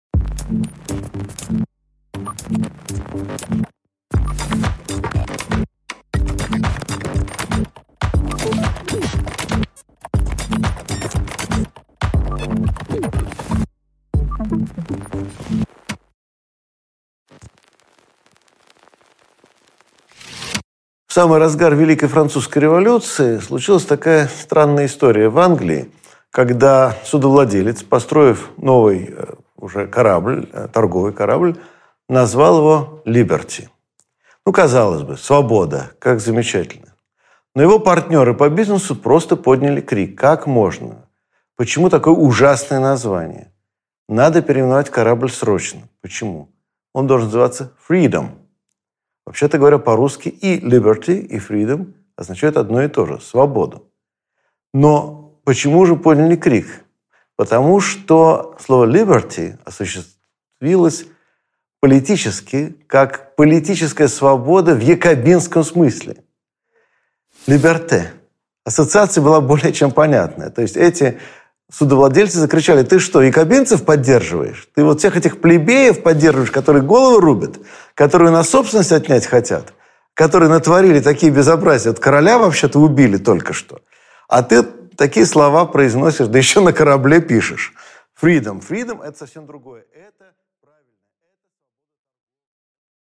Аудиокнига Свобода и «свобода» | Библиотека аудиокниг